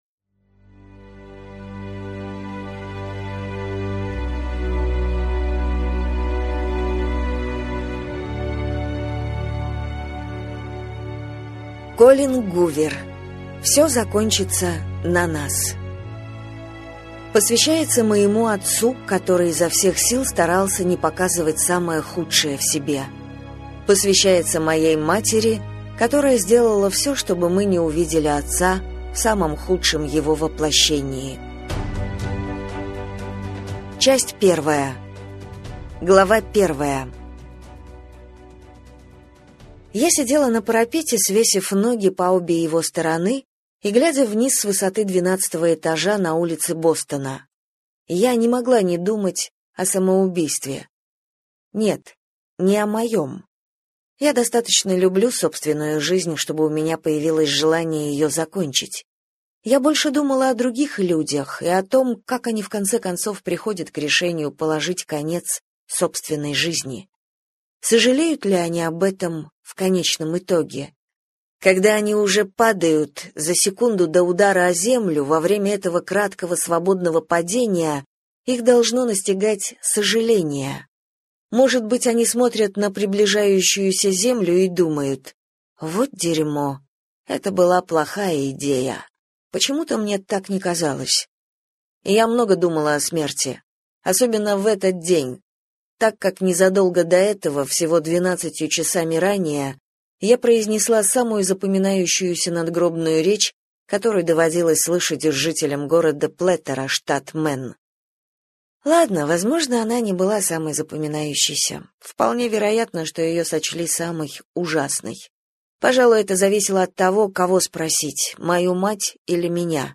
Аудиокнига Все закончится на нас | Библиотека аудиокниг